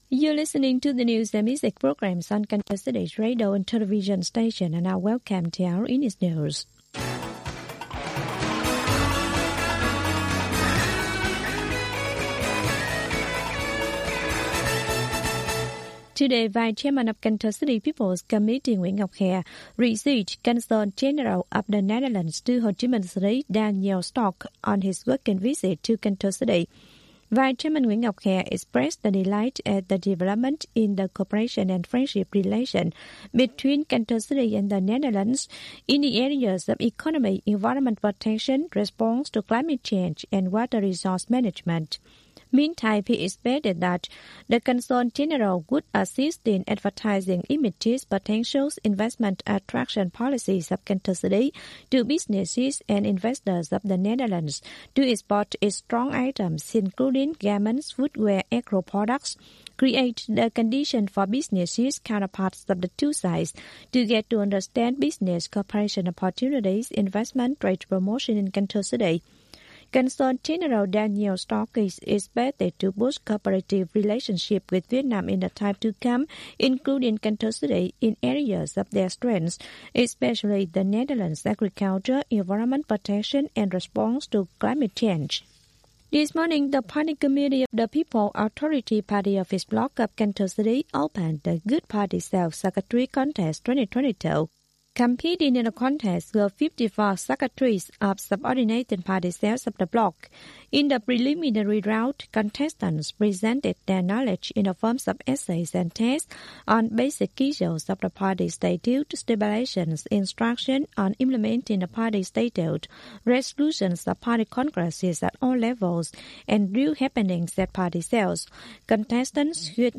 Bản tin tiếng Anh 10/5/2022